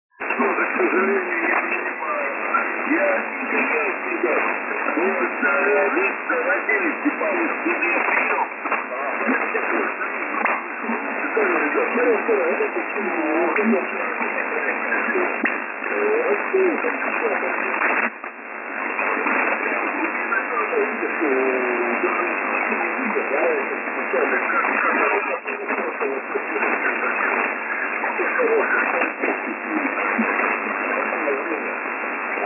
Слушал smile фулюганов на частоте 10,460мгц ,занятно,жизнь кипит
Возможно,но при приеме без примочек сложно вытянуть сигнал из эфира,я не стал записывать шумы без обработки,для сравнения нужно наверно было